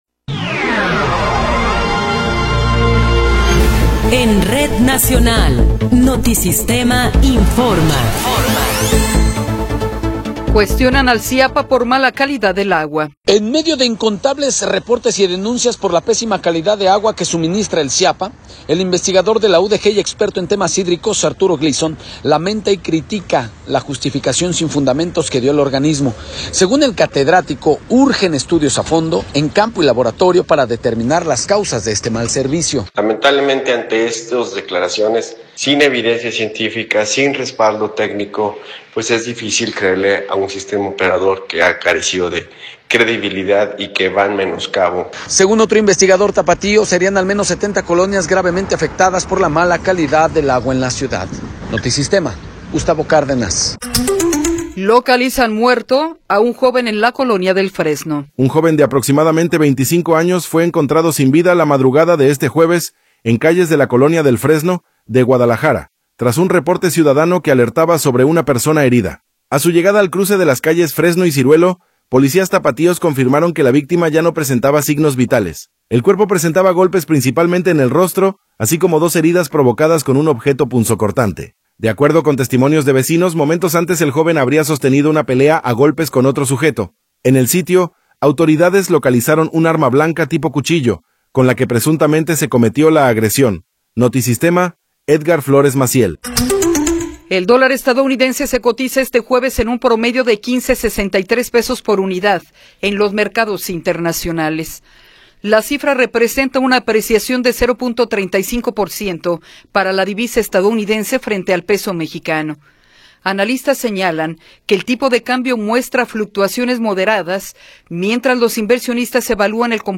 Noticiero 10 hrs. – 5 de Marzo de 2026